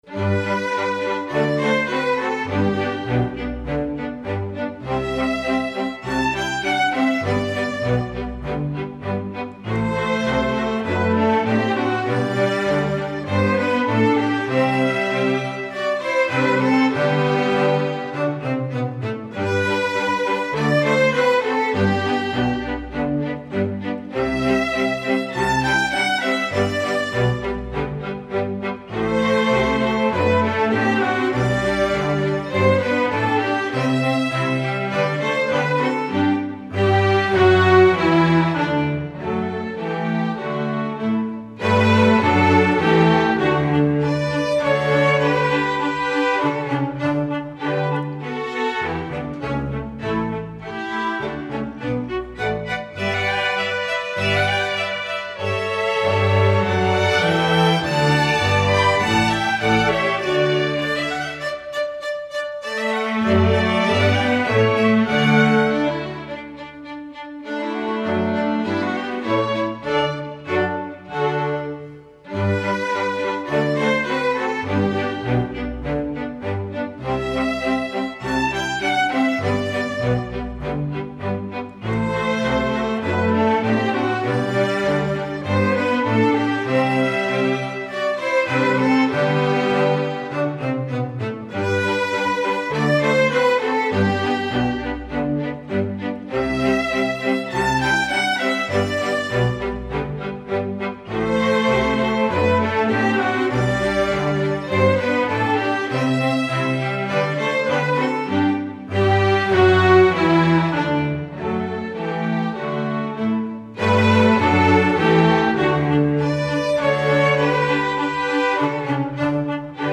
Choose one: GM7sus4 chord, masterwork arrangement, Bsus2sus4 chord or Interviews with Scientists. masterwork arrangement